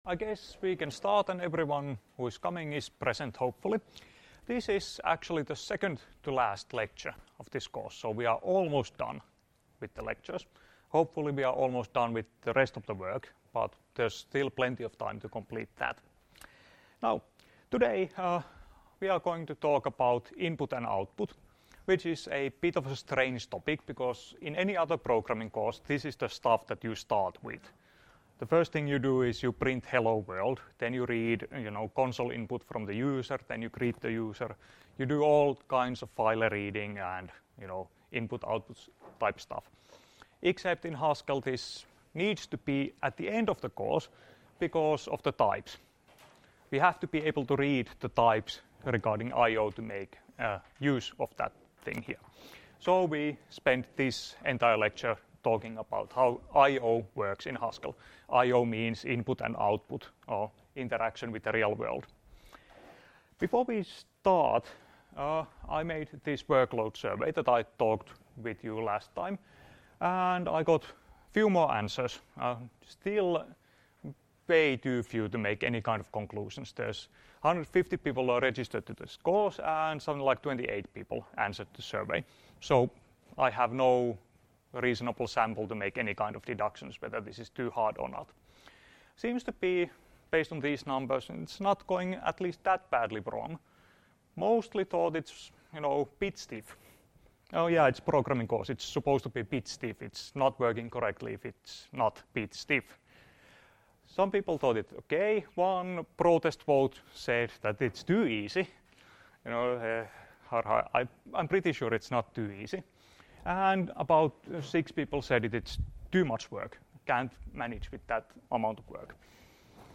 Luento 27.2.2017 — Moniviestin